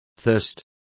Complete with pronunciation of the translation of thirsting.